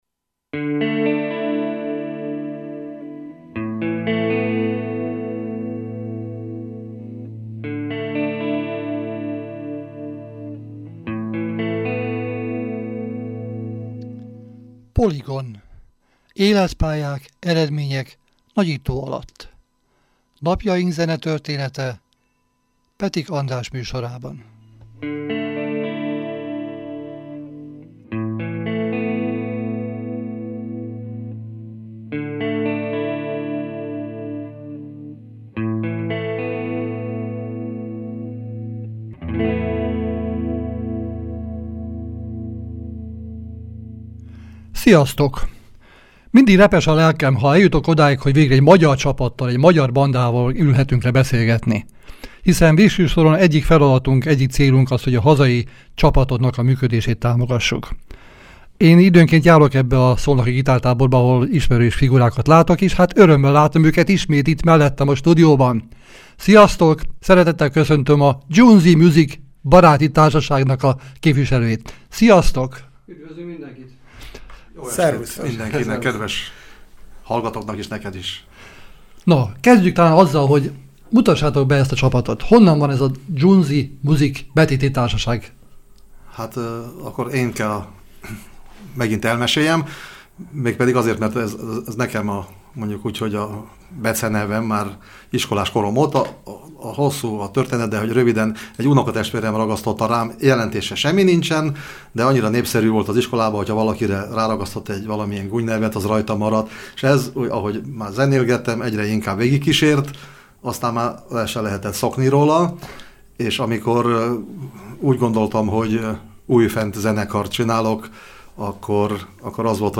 2024. október 22-én egy jó hangulatú zenés beszélgetésen vettünk részt a Civil Rádió Poligon c. műsorában. Sok zenével sok történettel gyorsan repült az idő a stúdióban!